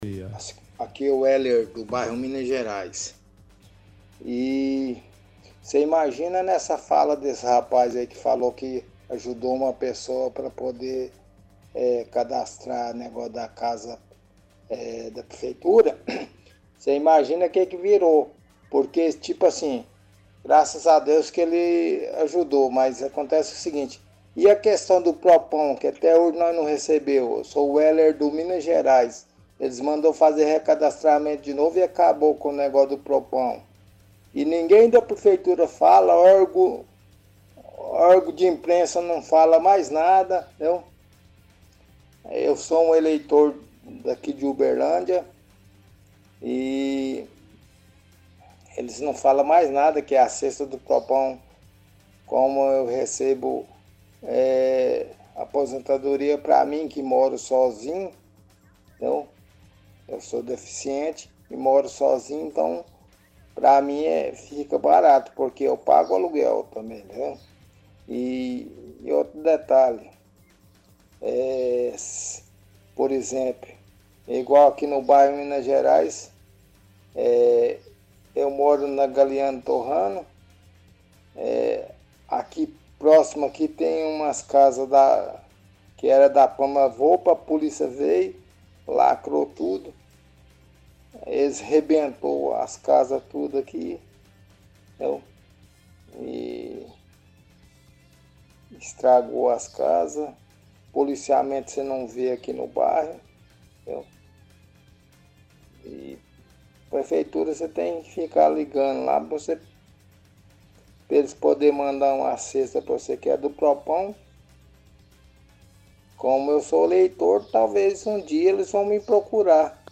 – Ouvinte do bairro Minas Gerais reclama que não consegue se recadastrar para receber um benefício da prefeitura que o entregava cesta do PRO-PÃO.